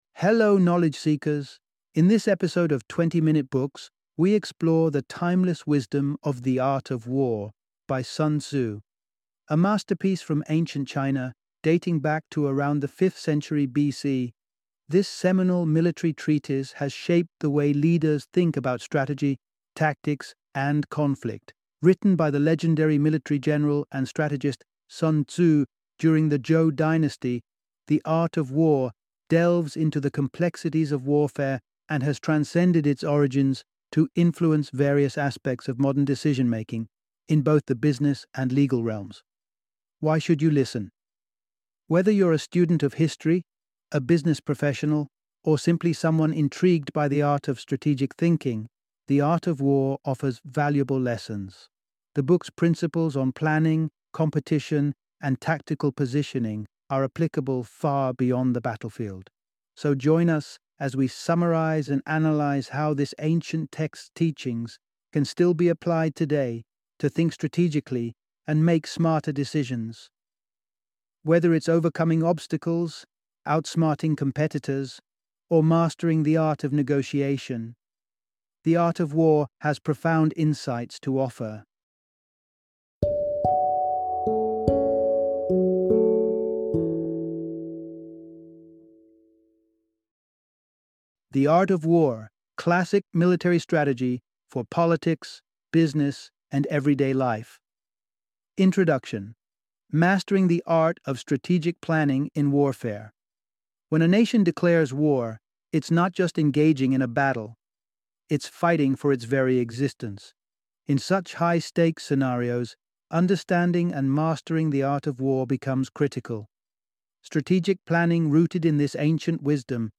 The Art of War - Audiobook Summary